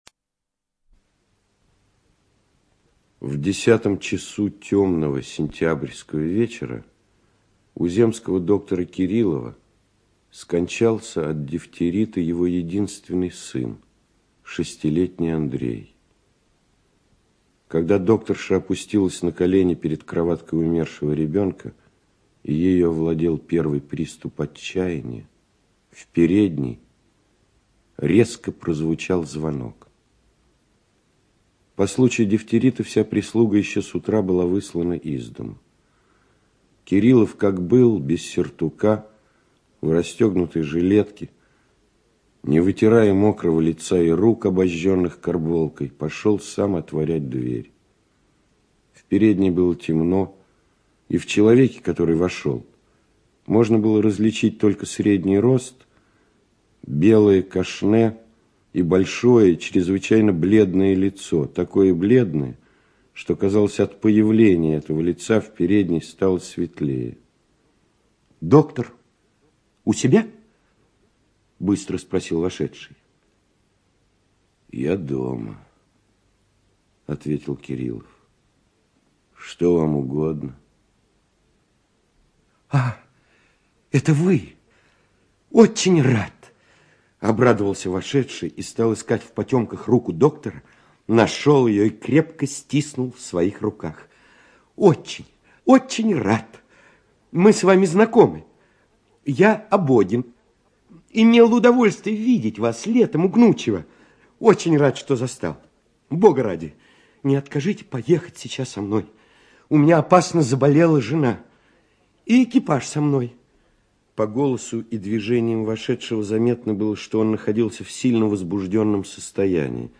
ЧитаетКваша И.
Чехов А - Враги (Кваша И.)(preview).mp3